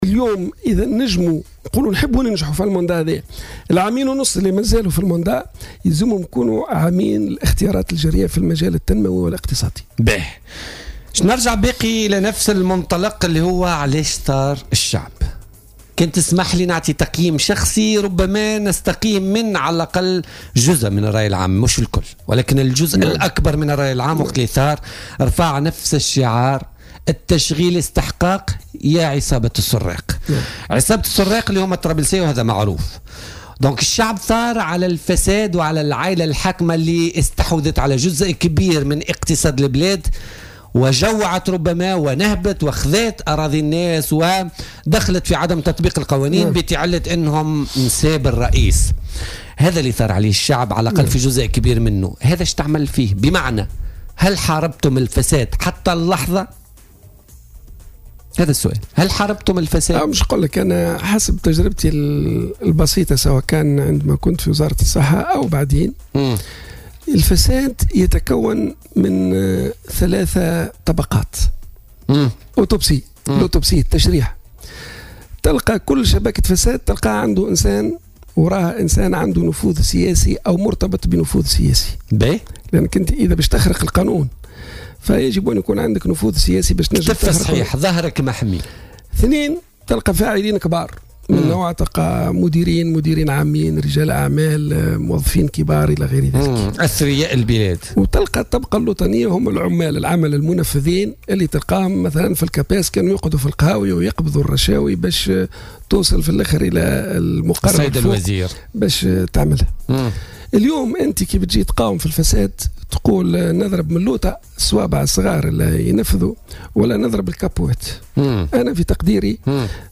وتابع ضيف "بوليتيكا" أن هذه الطبقات تتمثل في النافذين السياسيين والفاعلين الكبار والعمال المنفذين بحسب تعبيره.